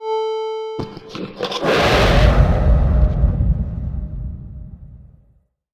spearYellRumbleVoicePA.ogg